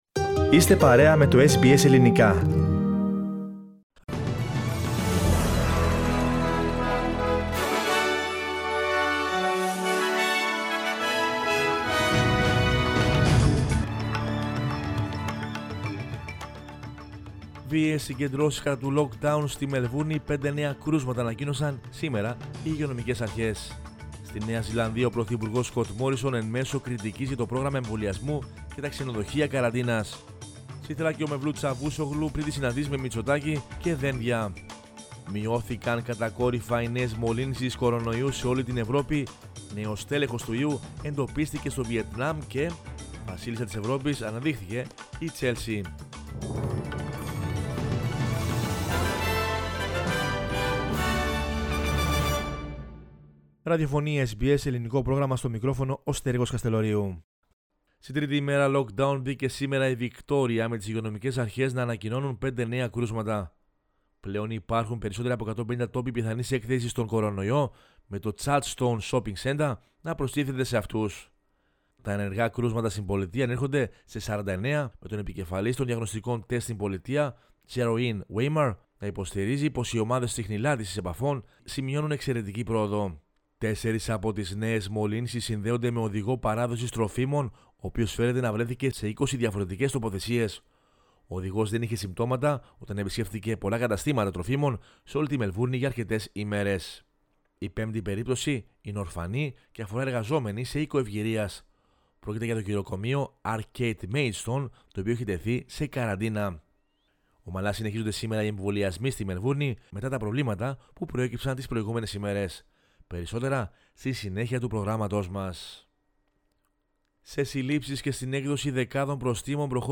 News in Greek from Australia, Greece, Cyprus and the world is the news bulletin of Sunday 30 May 2021.